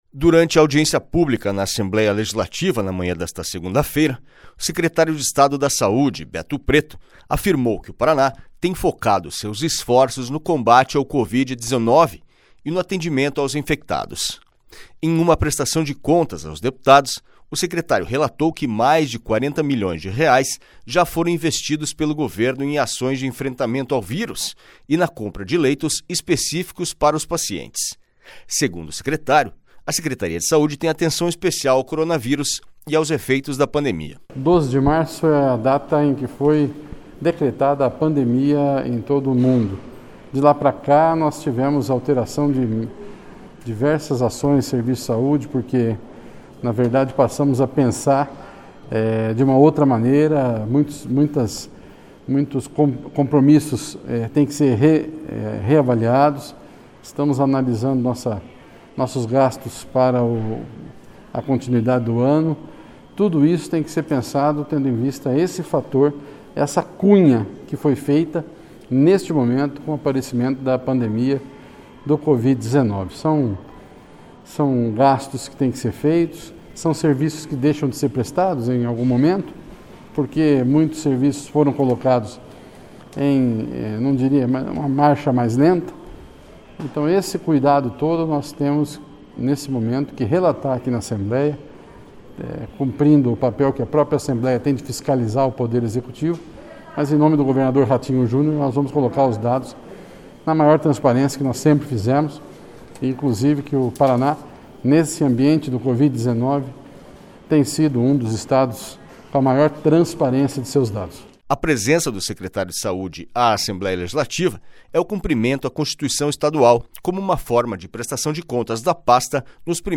Durante audiência pública na Assembleia Legislativa, na manhã desta segunda-feira (1), o secretário de Estado da Saúde, Beto Preto, afirmou que o Paraná tem focado seus esforços no combate ao Covid-19 e no atendimento aos infectados.
SONORA BETO PRETO
SONORA ADEMAR TRAIANO
SONORA DOUTOR BATISTA